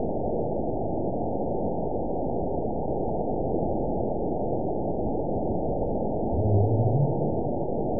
event 920418 date 03/24/24 time 00:01:55 GMT (1 year, 1 month ago) score 9.07 location TSS-AB02 detected by nrw target species NRW annotations +NRW Spectrogram: Frequency (kHz) vs. Time (s) audio not available .wav